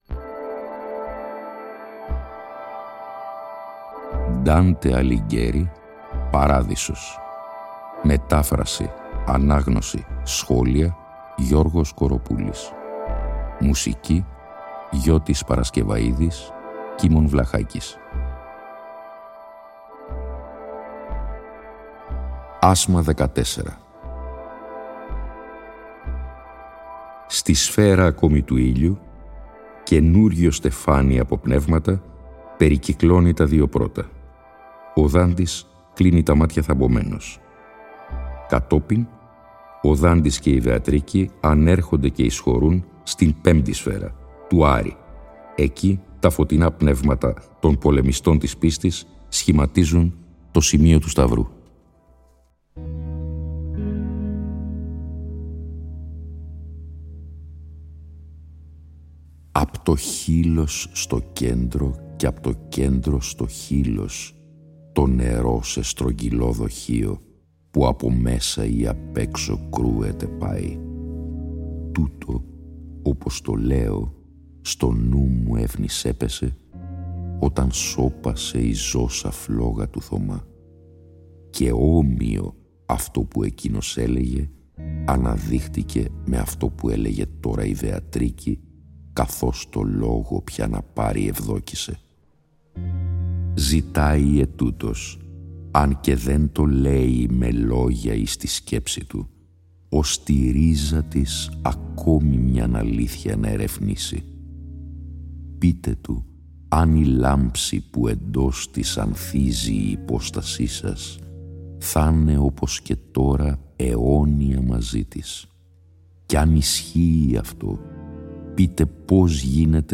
συνυφαίνεται και πάλι με μουσική